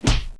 PUNCH18.WAV